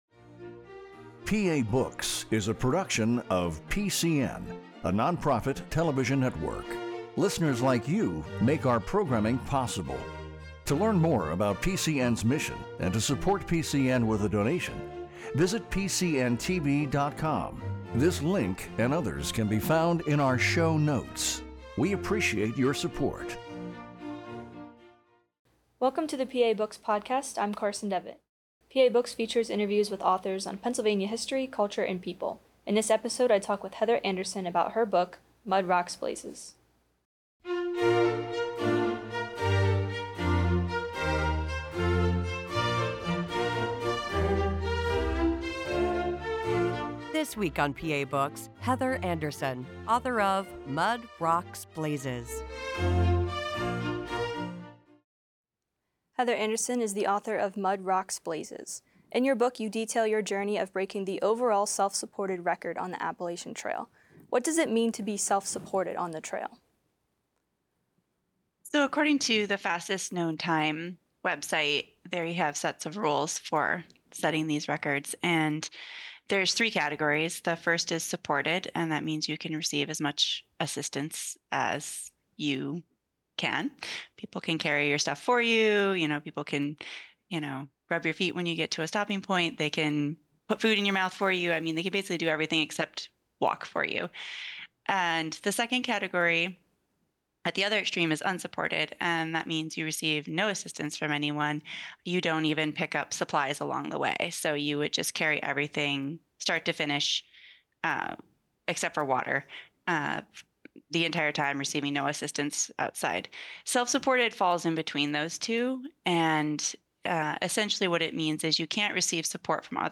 PA Books features authors of books about Pennsylvania-related topics. These hour-long conversations allow authors to discuss both their subject matter and inspiration behind the books.